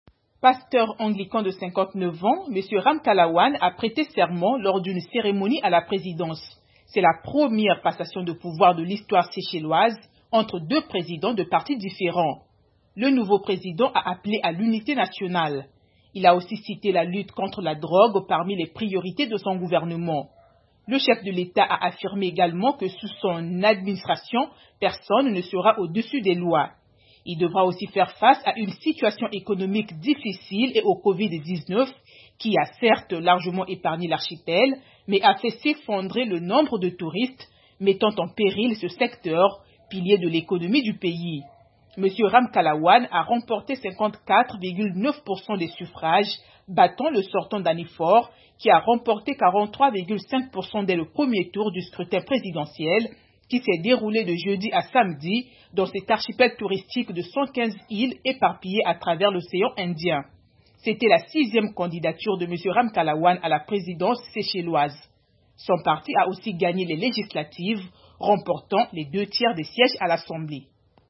Wavel Ramkalawan, le nouveau président des Seychelles, prête serment